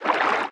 Sfx_creature_penguin_idlesea_A_05.ogg